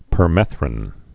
(pər-mĕthrĭn)